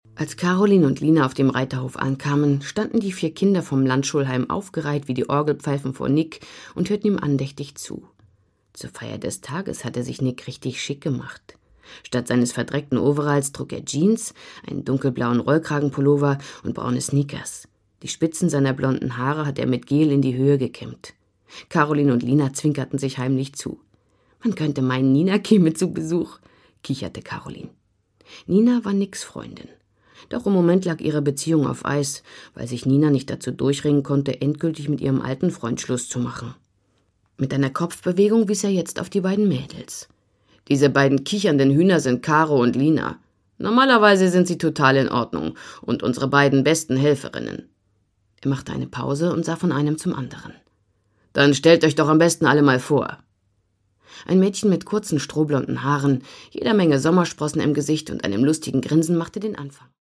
Produkttyp: Hörbuch-Download
Fassung: gekürzte Fassung